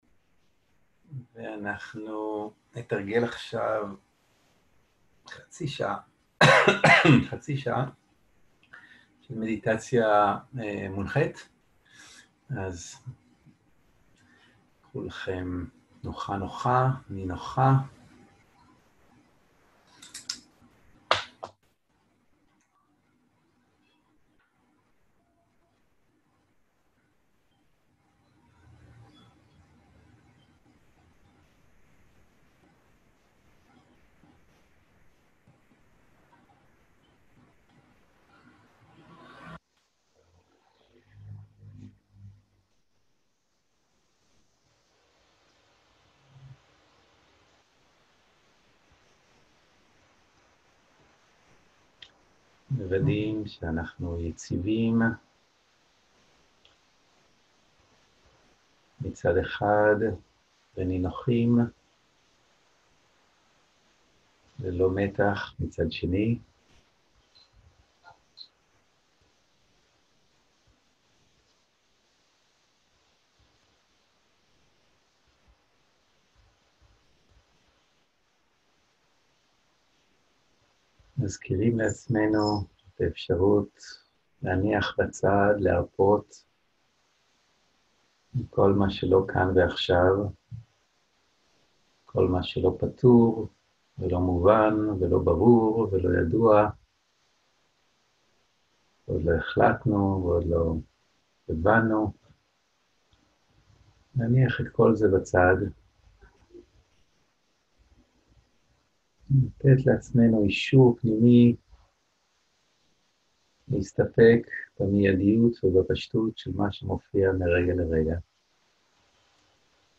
מדיטציה מונחית
סוג ההקלטה: מדיטציה מונחית
איכות ההקלטה: איכות גבוהה